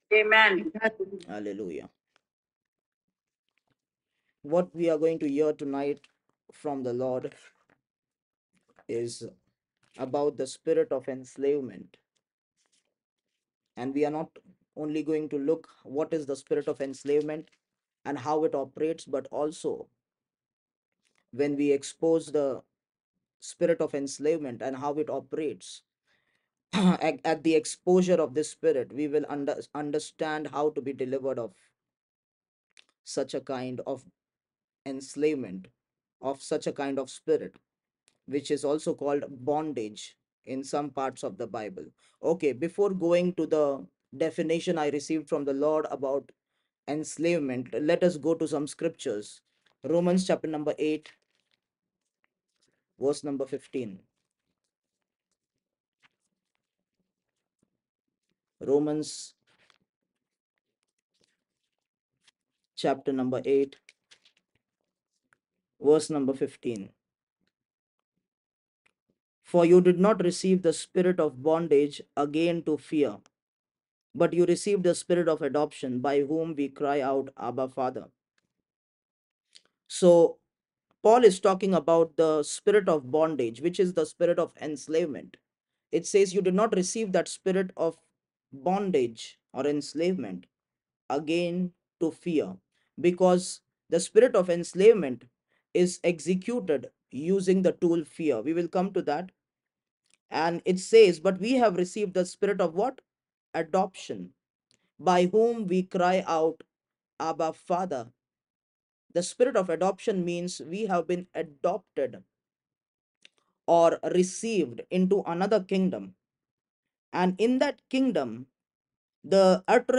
audio sermons - Spirit of enslavement Part 1 | Overcoming the Force of the Enemy